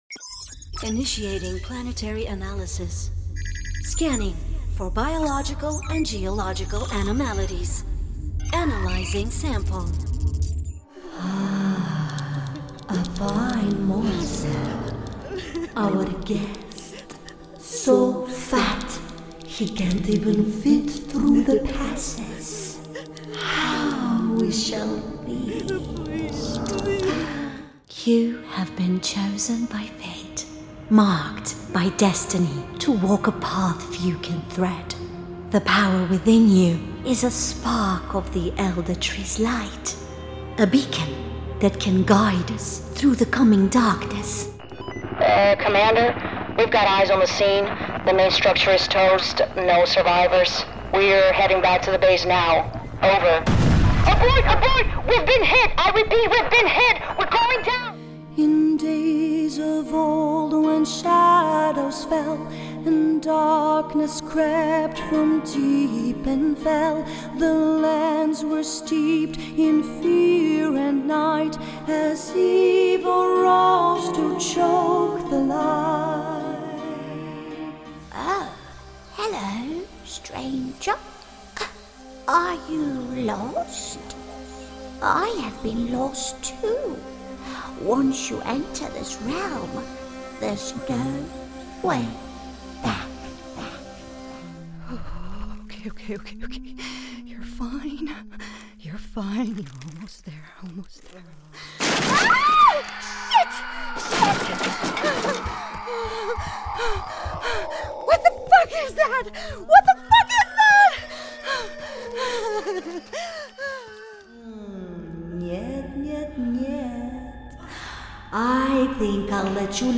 Female
Bright, Bubbly, Character, Children, Confident, Smooth, Warm, Versatile
Transatlantic, East-European, French, Middle-Eastern
Microphone: Scarlett Solo Studio, AKG Lyra, Rode NT1a
Audio equipment: I have a soundproof studio booth, I record using focusrite, LogicPro X om my MacBook Pro